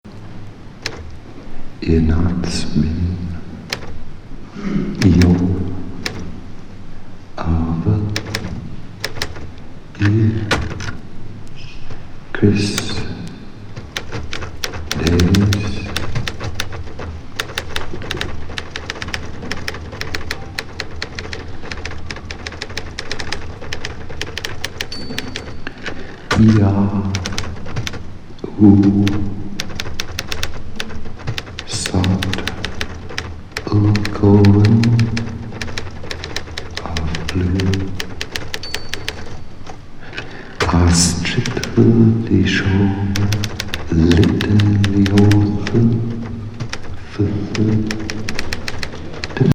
ドイツはシュトゥットガルトでの75年ライブ録音!プリペアド・ピアノの為のソナタ
広大なサウンド!